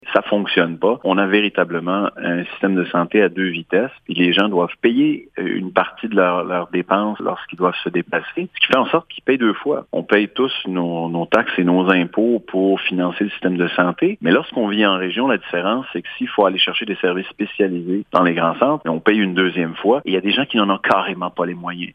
Ce que dénoncent ces organismes, c’est la politique de déplacement des usagers dont seulement une fraction des frais est couverte par le système de santé québécois. Le député au parti québécois affirme qu’il s’agit d’une injustice pour les Québécois résidants en région :